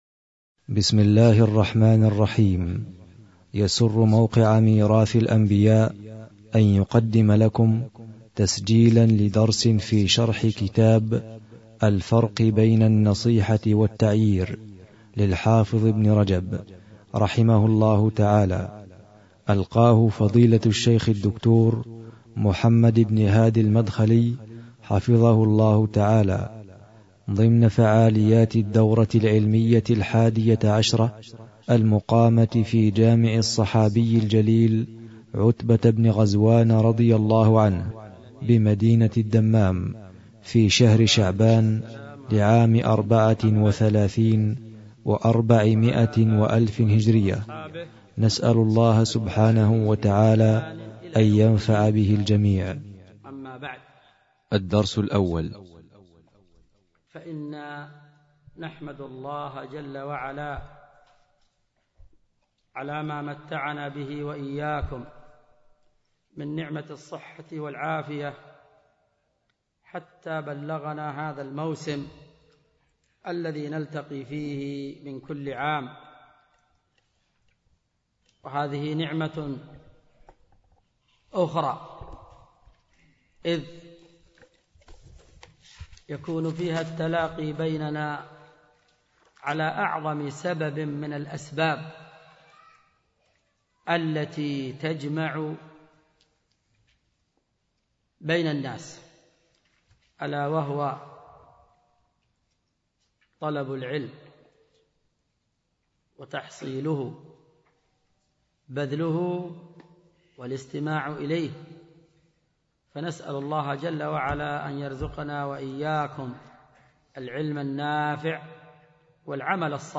الدرس 1